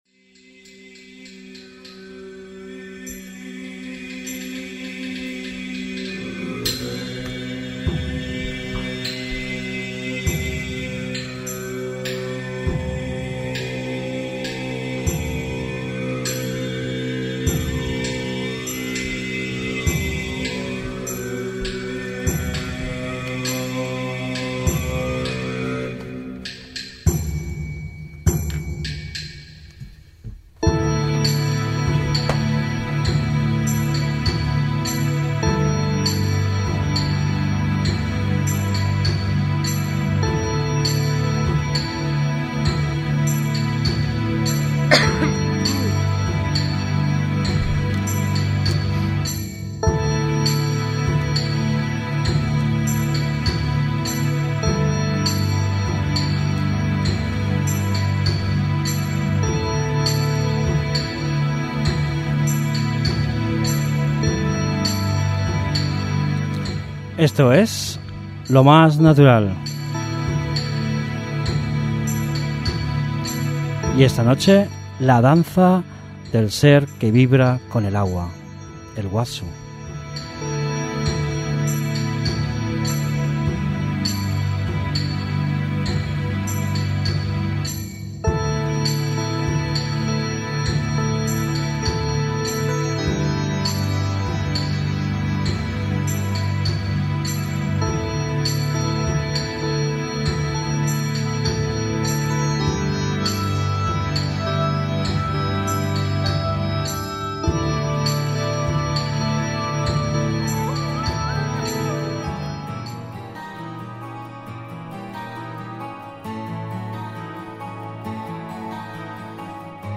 Un programa en directo dedicado a lo m�s profundo del ser humano. El equipo lo constituyen varios psic�logos, naturistas, artistas, fil�sofos, un esteopata y un soci�logo, un grupo de amigos con un planteamiento com�n: �se puede vivir con un estilo de vida con calidad donde no te sientas manipulado, pues tu elijes, dando un sentido como ser �nico e irrepetible en armon�a con tu entorno, siendo librepensador.